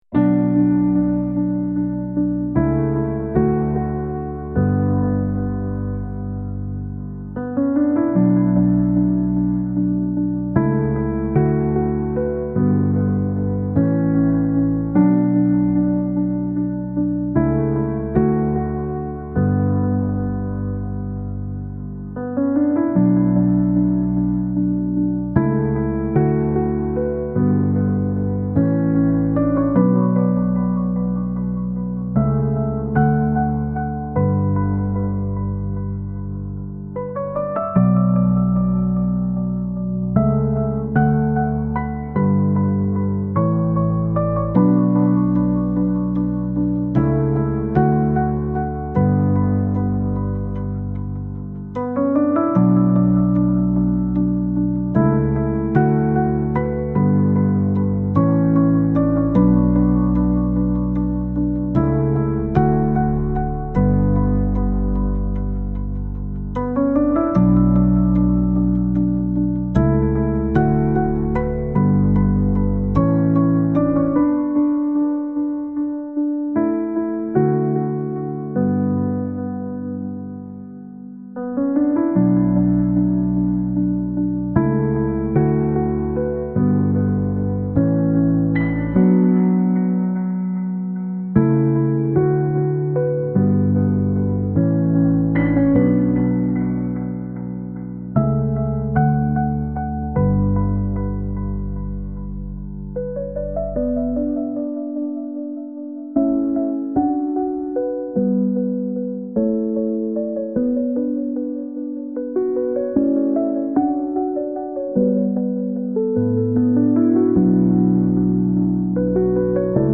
Instrumental.